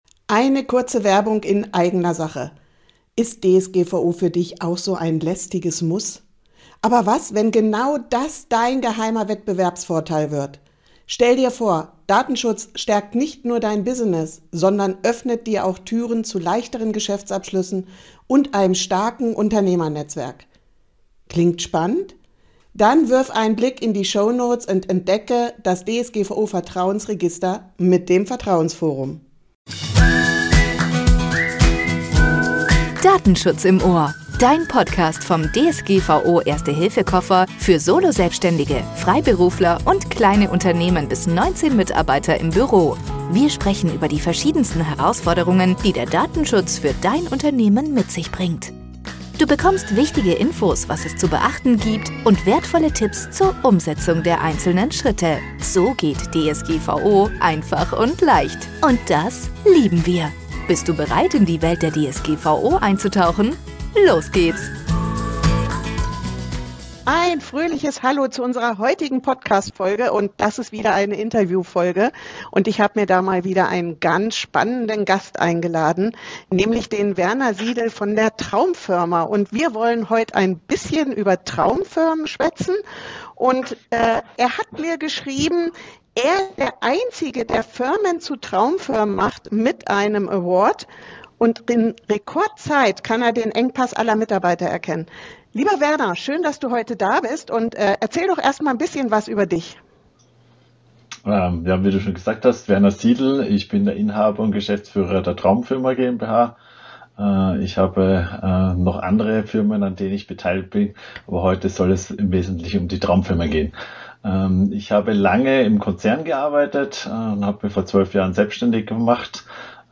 #27 Datenschutz und Cyberversicherung als Rettungsanker für Ihr Unternehmen - Interview